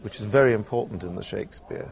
He’s not mumbling or mispronouncing – such weak syllables are a fundamental part of English.